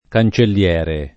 kan©ellL$re] s. m.; f. -ra — sim. i cogn. Cancelliere, Cancellieri — da quest’ultimo casato il nome della parte cancelliera [p#rte kan©ellL$ra] nella storia medievale di Pistoia